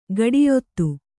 ♪ gaḍiyottu